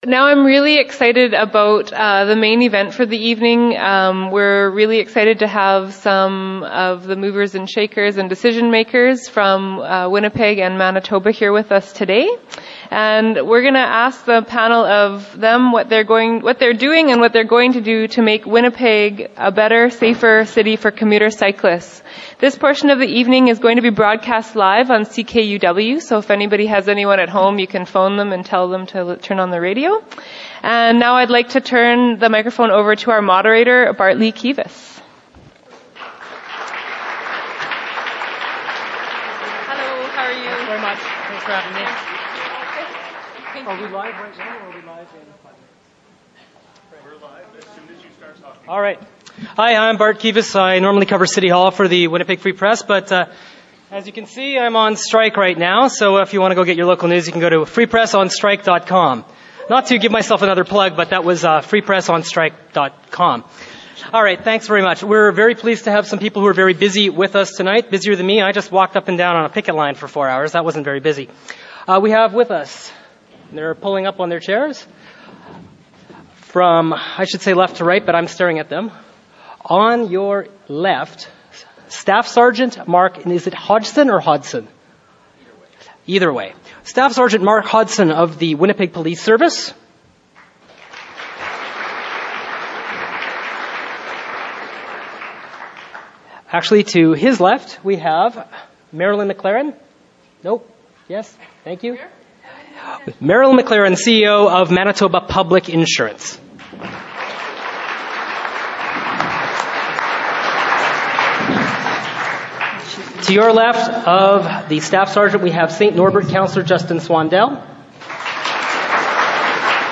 The forum aired live at 7:30pm on Wednesday Oct 15th.